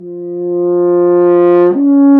Index of /90_sSampleCDs/Roland L-CDX-03 Disk 2/BRS_F.Horn FX/BRS_Intervals
BRS F HRN 0E.wav